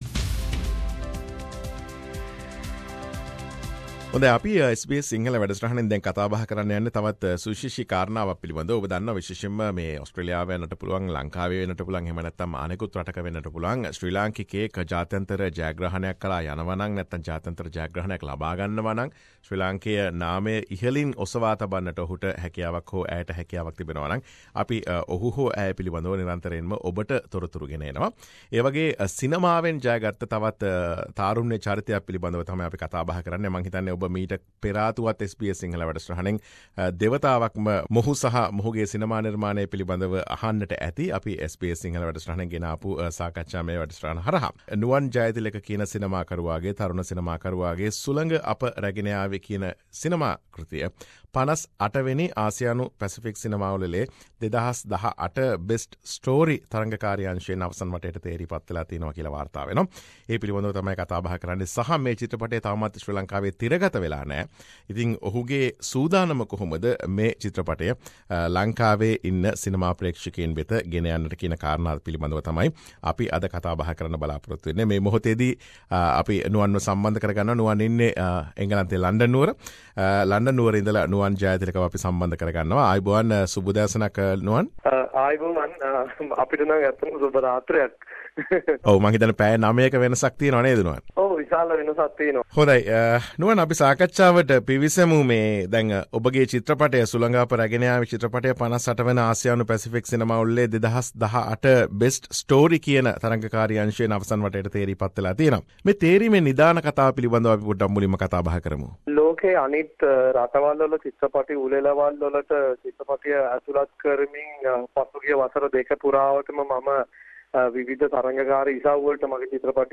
කතා බහකි මේ...